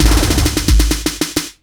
Roll_Up.wav